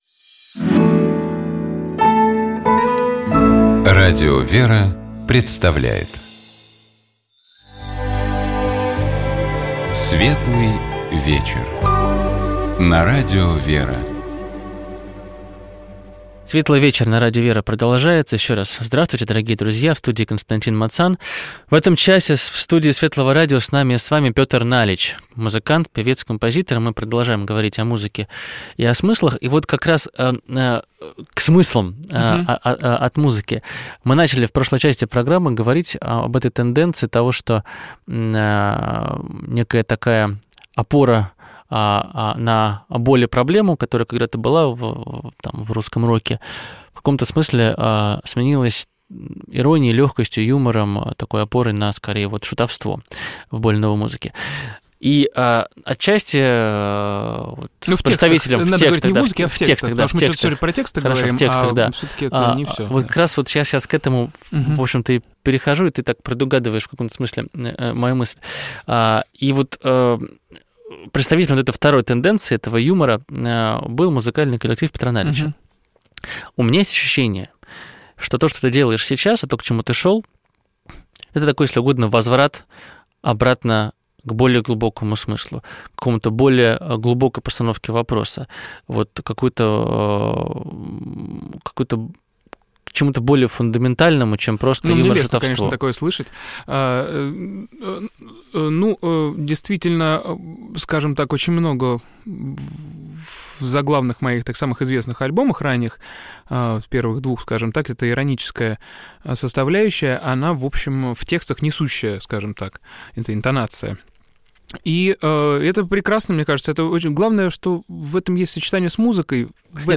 У нас в гостях певец, композитор Петр Налич.